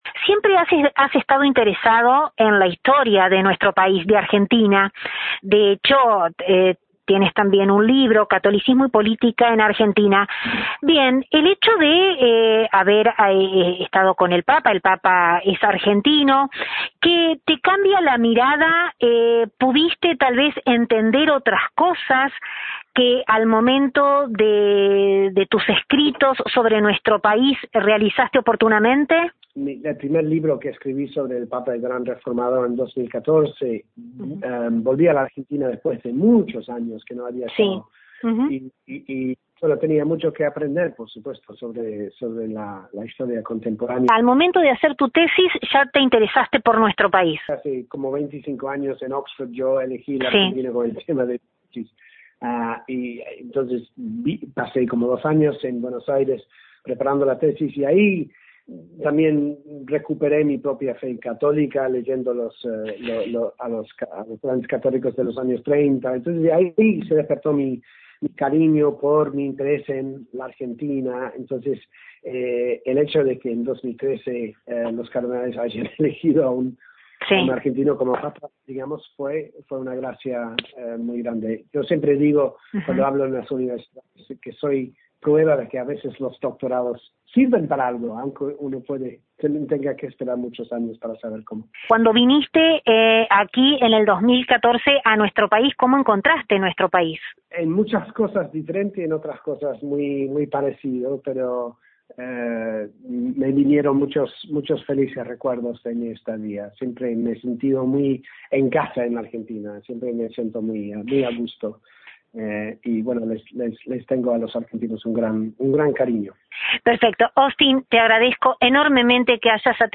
En la última parte de la entrevista, hablamos sobre su estrecha relación con la República Argentina; ya que según destacó, que más allá de haber estudiado calificada y a través de altos estudios, nuestra historia contemporánea política-religiosa; fue aquí, en nuestro país, donde afirmó su devoción cristiana.